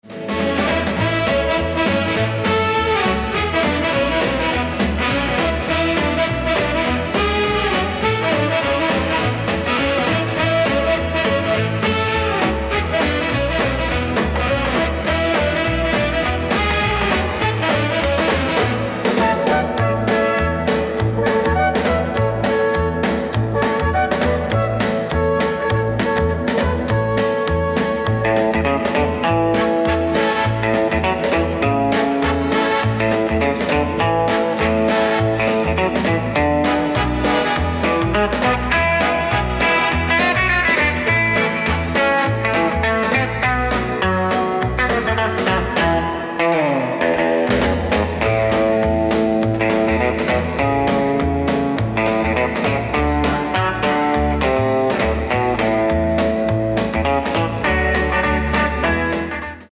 Original unter Country